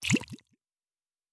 Potion and Alchemy 01.wav